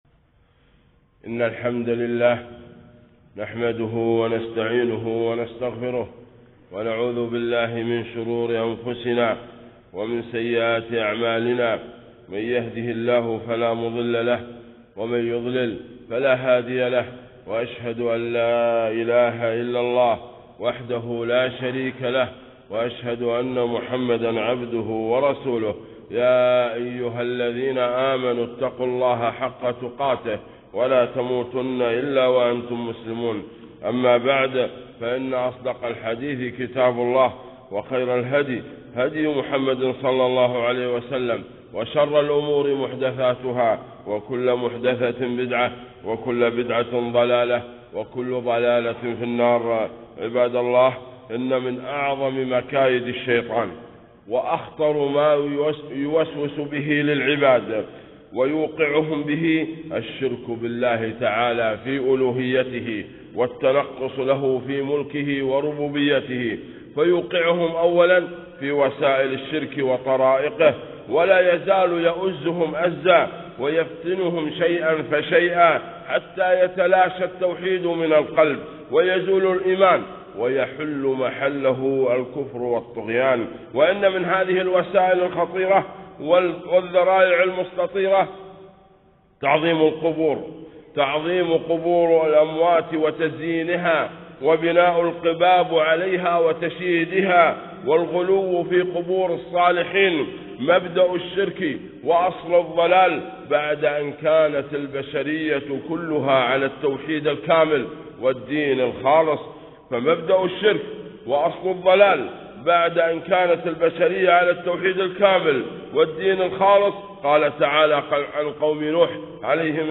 خطبة - آداب المقابر